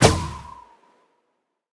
sounds / mob / breeze / jump1.ogg
jump1.ogg